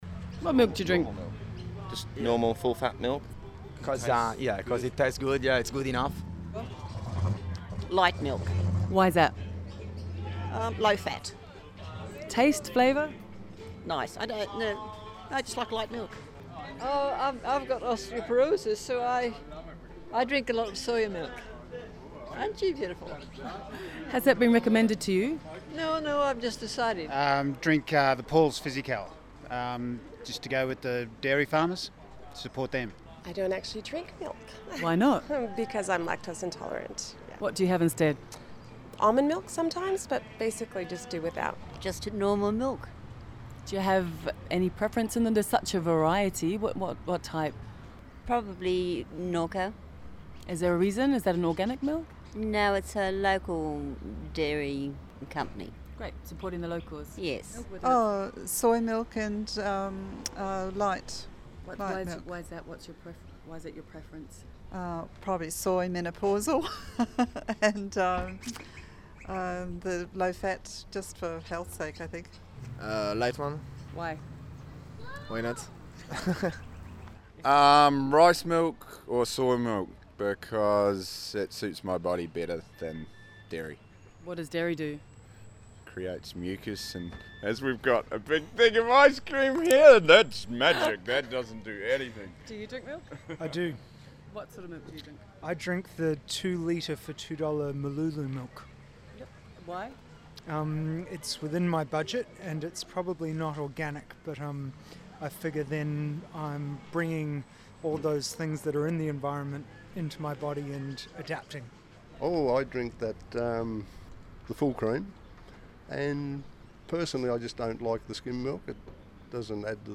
Where better to stand but outside one of our local supermarkets.
It was me gently nudging a microphone in the happy faces of you shoppers and passers by, trying to get you to part with your voice as well as your thoughts and opinions so that i could get an idea of not only the diversity of you lovely people in the Shire on this particular day, but also the variations of your favorite white stuff.